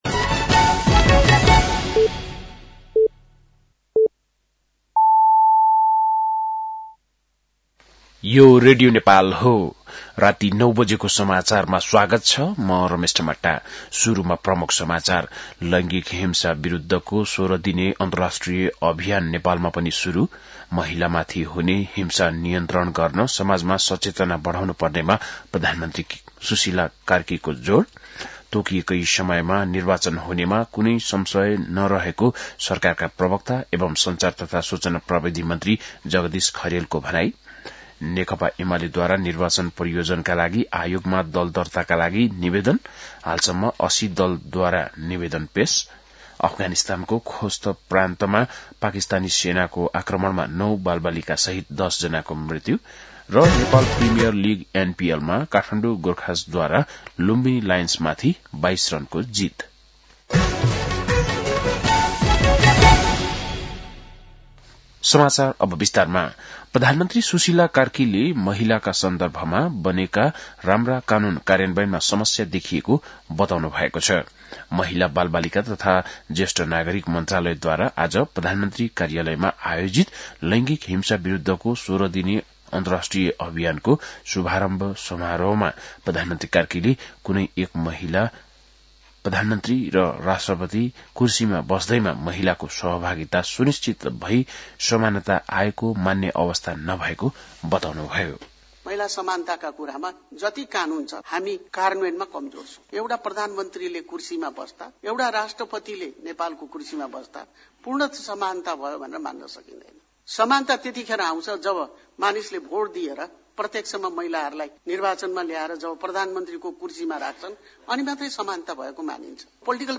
बेलुकी ९ बजेको नेपाली समाचार : ९ मंसिर , २०८२